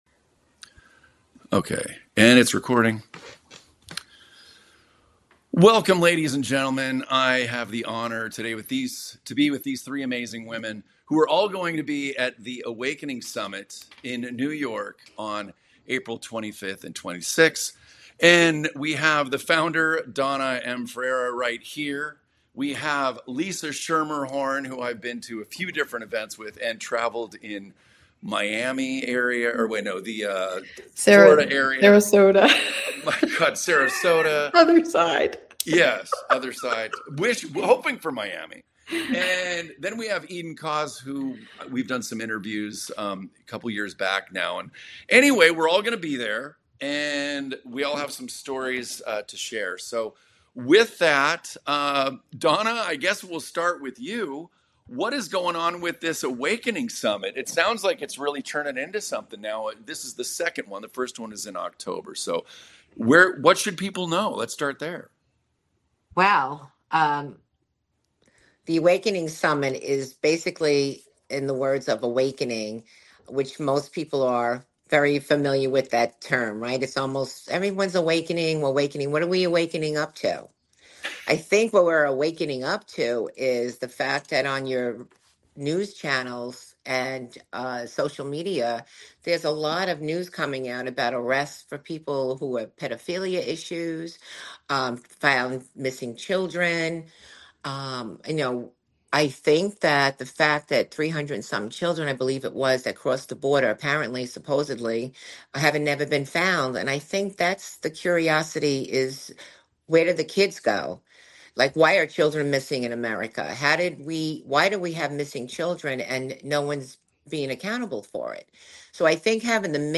Join us at the Awakening Summit in New York, where speakers tackle the critical issue of missing children worldwide. This event aims to raise awareness, inspire action, and offer hope.